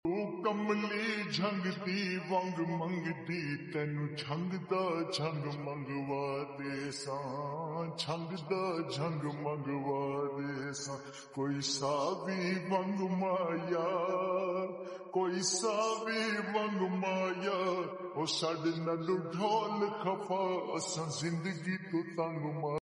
12 inches subwoofer 6000#unfrezzmyaccount #1millionaudition